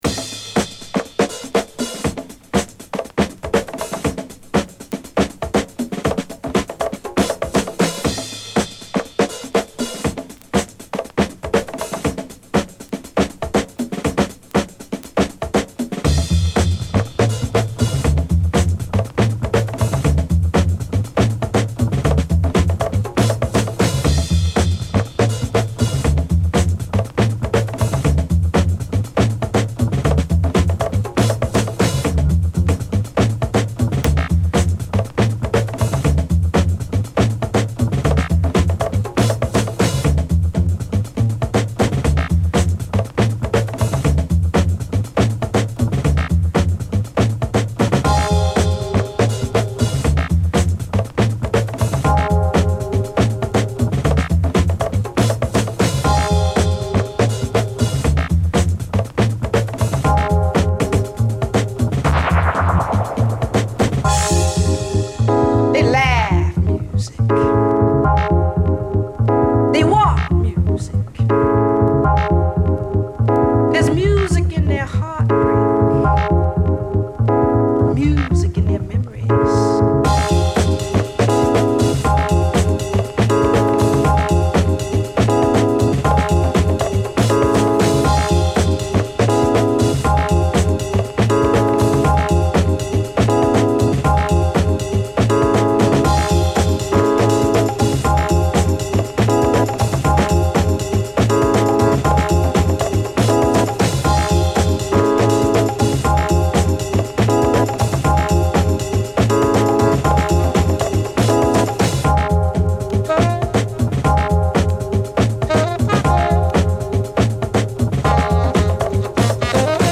Home > Break Beats